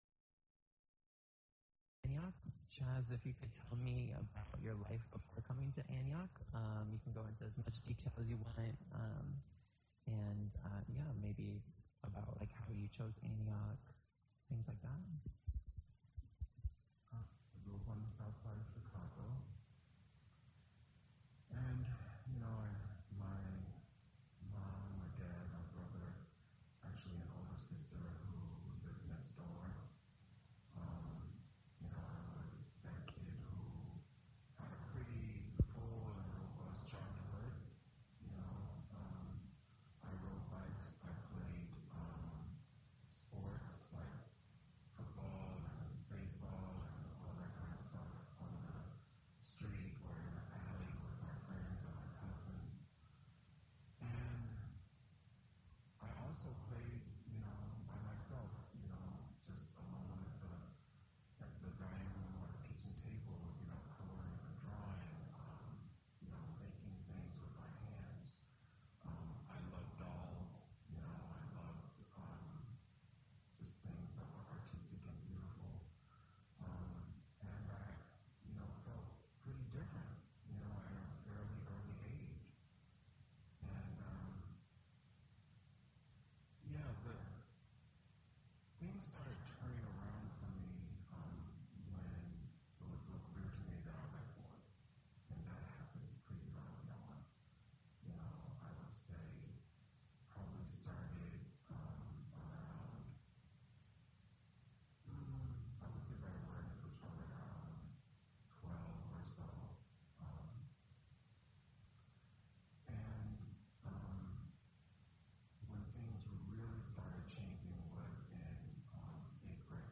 Oral History in the Liberal Arts | LGBTQ+ Experiences at Antioch College